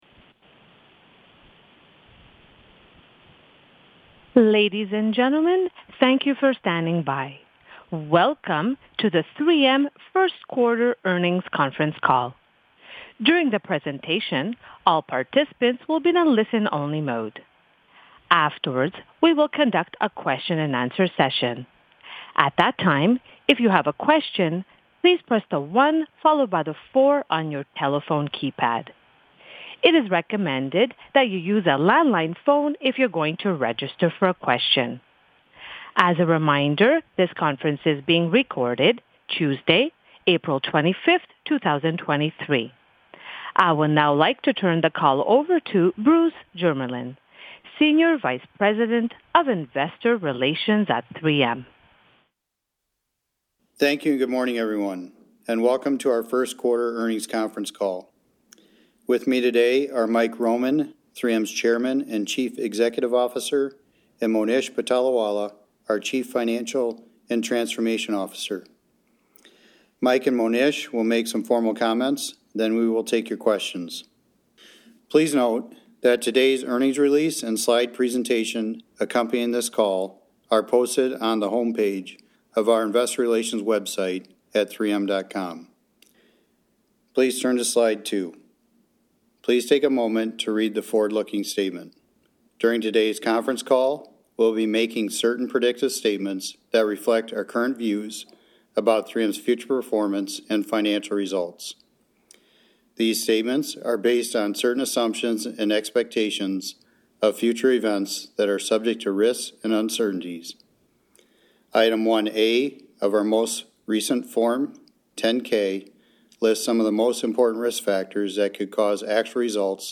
q1-2023-earnings-call.mp3